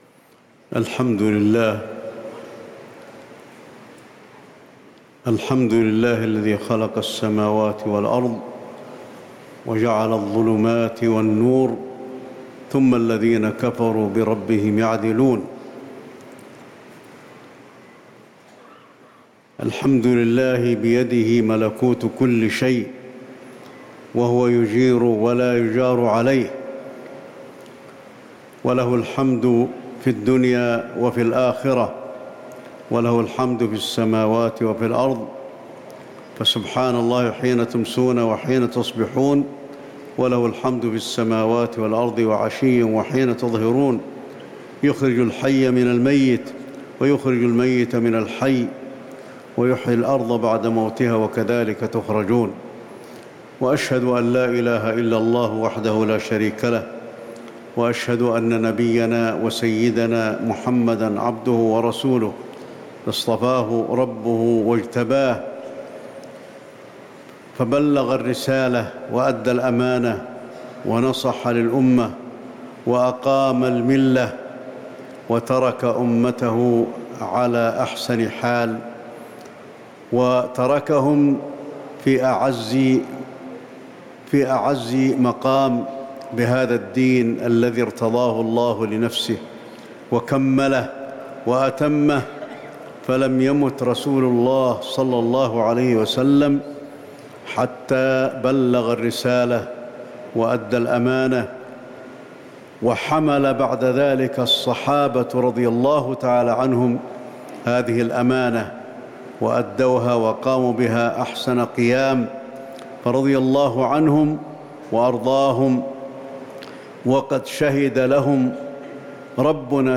خطبة الخسوف المدينة - الشيخ علي الحذيفي
تاريخ النشر ١٣ ذو القعدة ١٤٤٠ هـ المكان: المسجد النبوي الشيخ: فضيلة الشيخ د. علي بن عبدالرحمن الحذيفي فضيلة الشيخ د. علي بن عبدالرحمن الحذيفي خطبة الخسوف المدينة - الشيخ علي الحذيفي